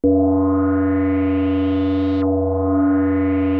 JUP 8 G4 9.wav